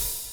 DISCO 13 OH.wav